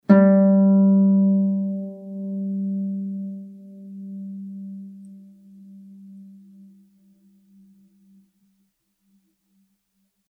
harp
Added sound samples